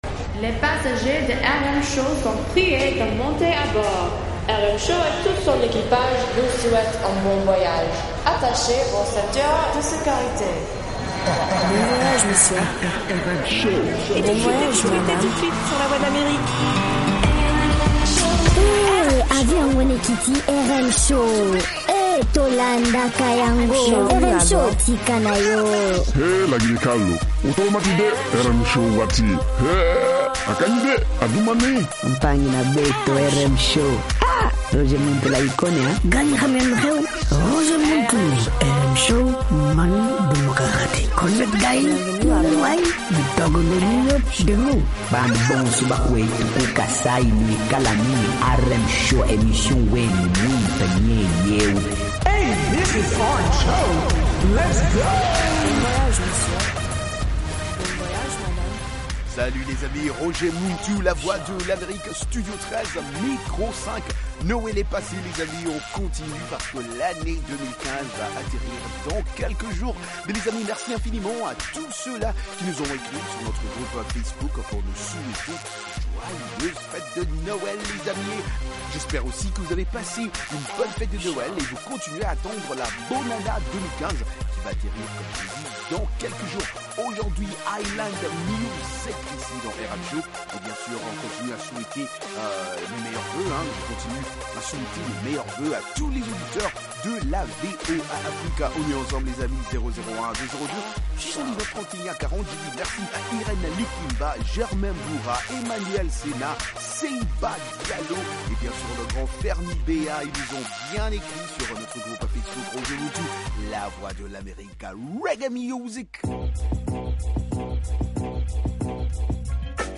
Ecoutez toute la musique des îles, Zouk, Reggae, Latino, Soca, Compas et Afro